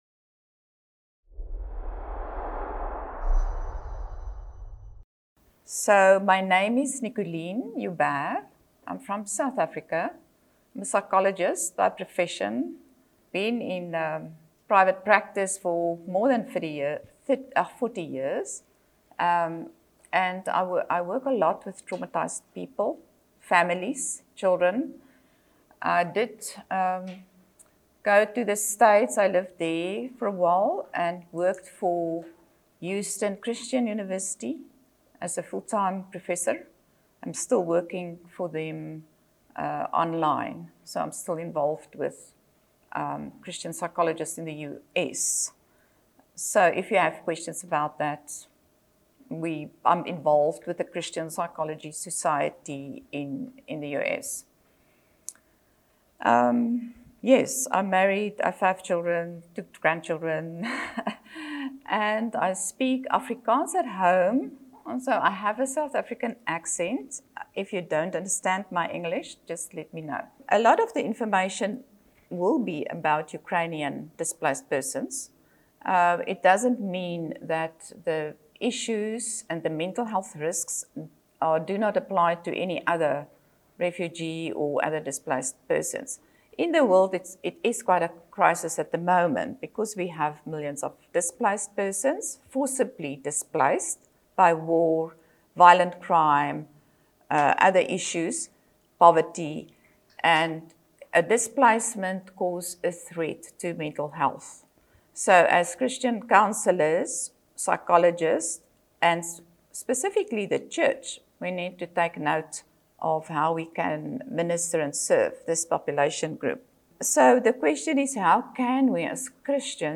Event: ELF Workshop